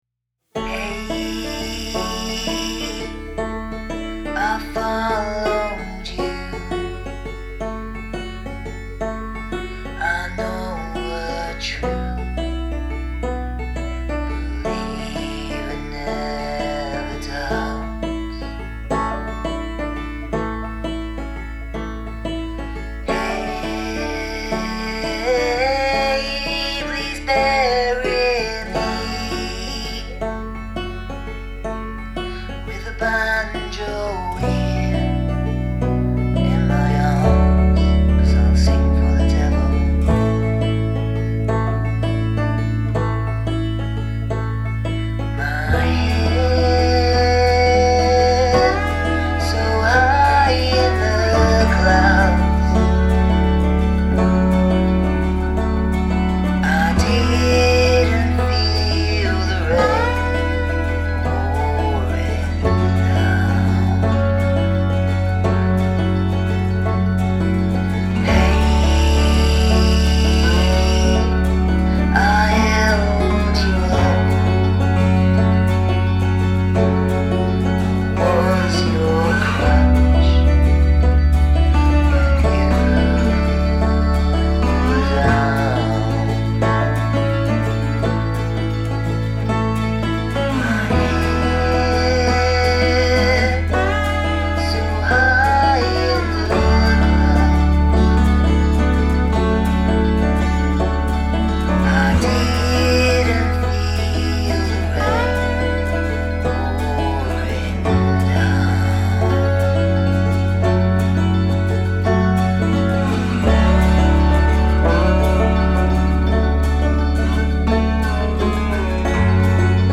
Male Vocal, banjo, acoustic lapsteel, mandolin, bass guitar